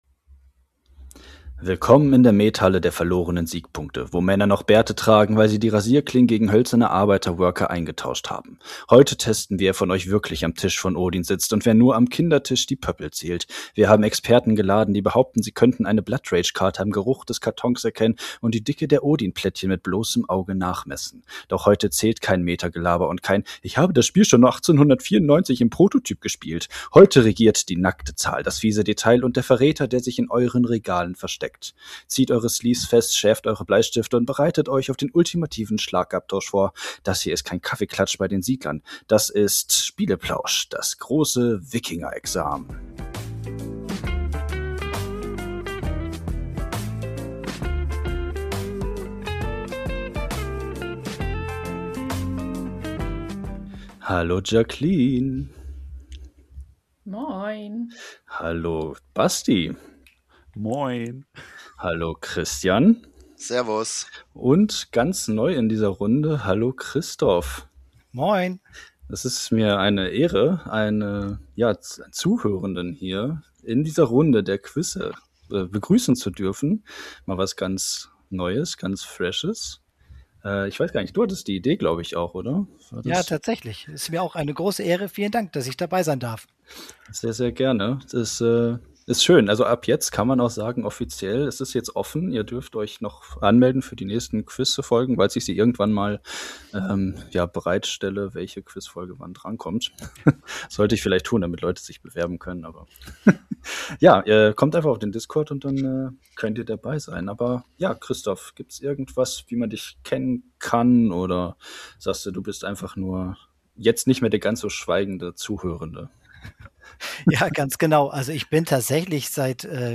Wir Quizzen wieder um die Wette. Wer kennt sich mit Wikingerspielen am besten aus?